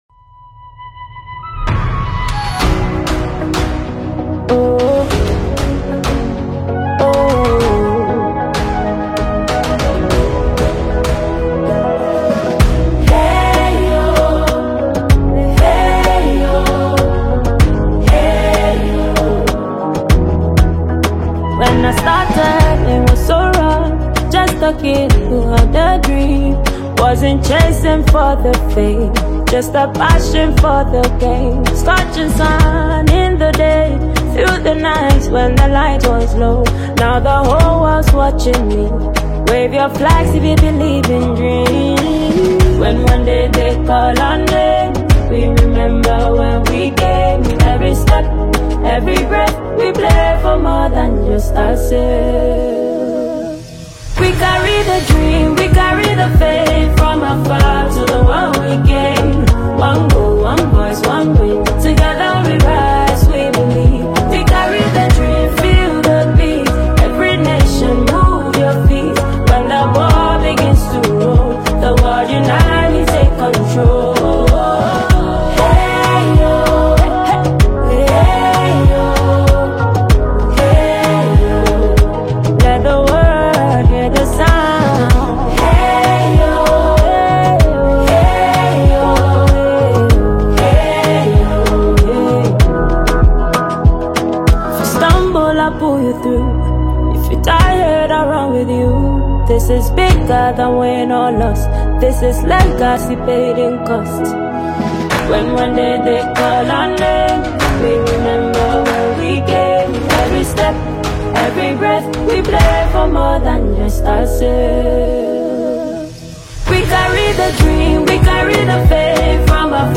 • Genre: Afrobeat / Dancehall